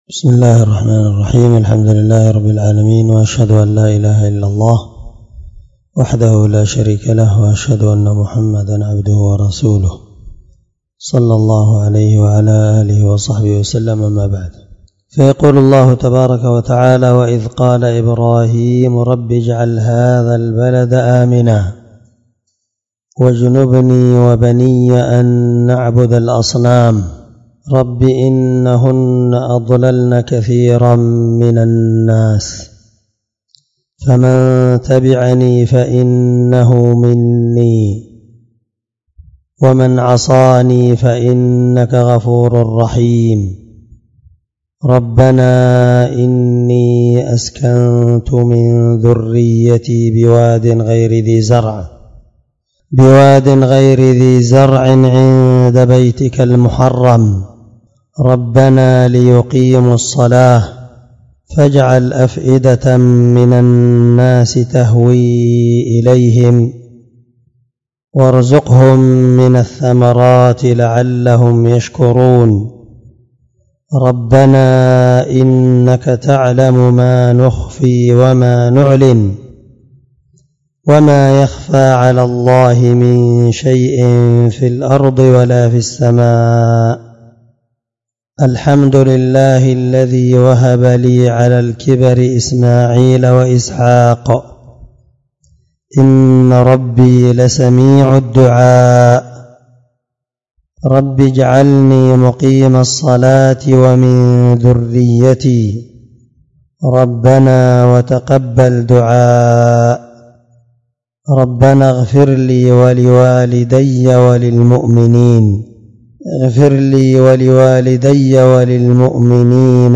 706الدرس13تفسير آية (35-41) من سورة إبراهيم من تفسير القرآن الكريم مع قراءة لتفسير السعدي